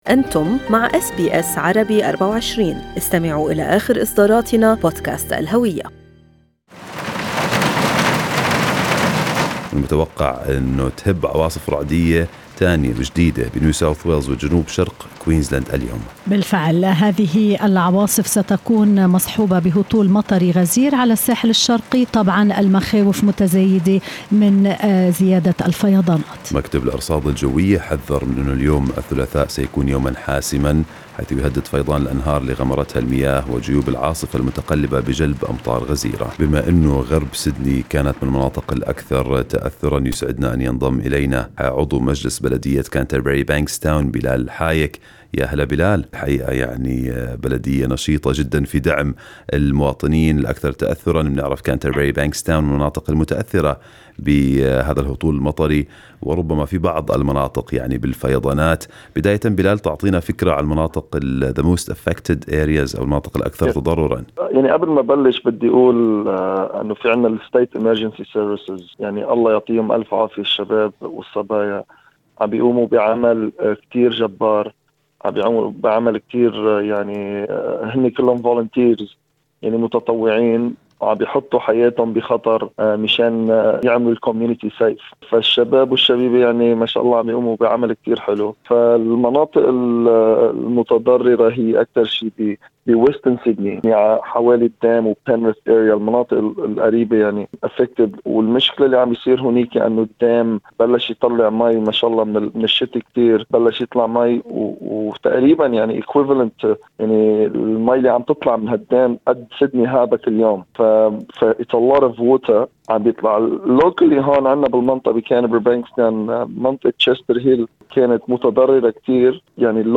عضو بلدية Canterbury-Bankstown يصف أضرار الأمطار والفيضانات في غرب سيدني